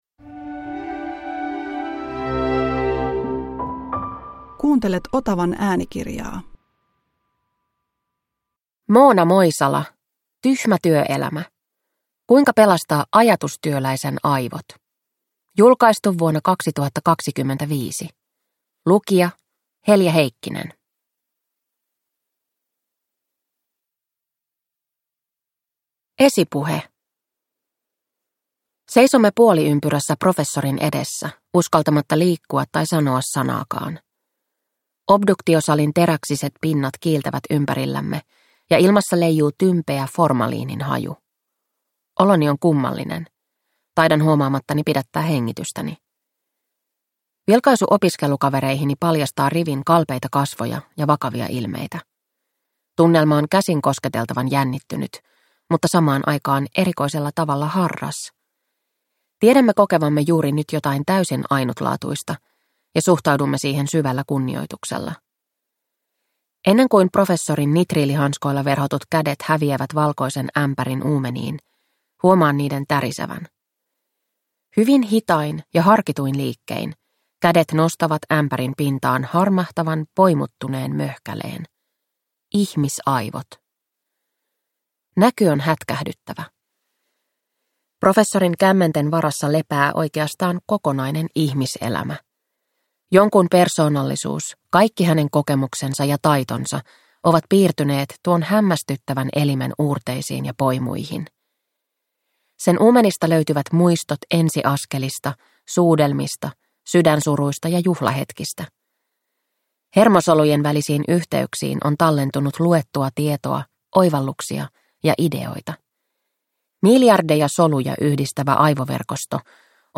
Tyhmä työelämä – Ljudbok